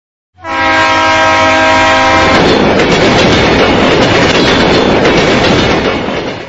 1 – نغمة صوت القطار